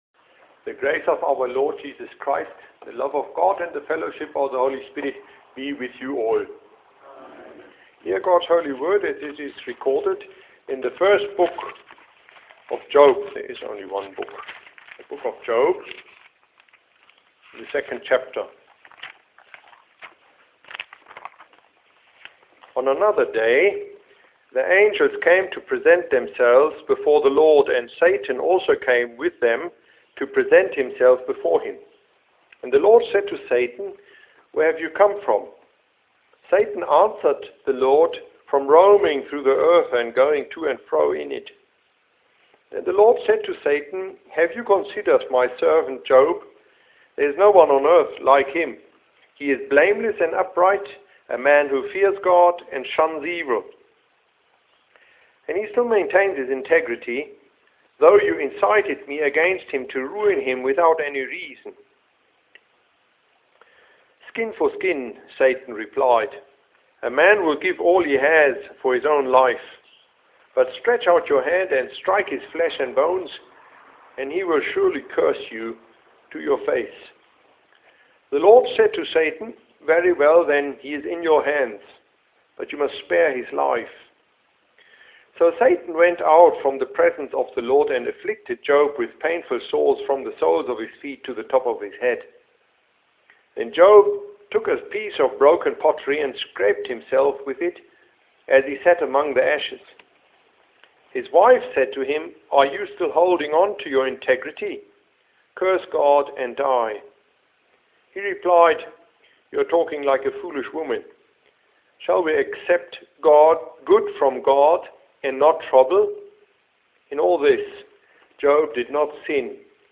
Confessional address